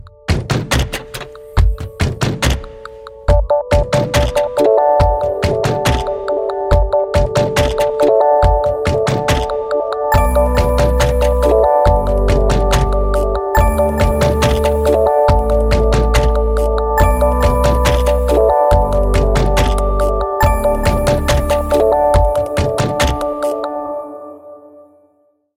mini-cooper_24623.mp3